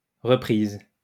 In music, a reprise (/rəˈprz/ rə-PREEZ,[1] French: [ʁəpʁiz]